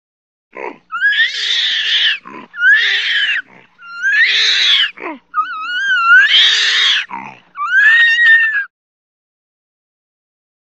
Orangutan Scream, Snort. Series Of Long, High Pitched Screams With Short Snorts In Between. Close Perspective.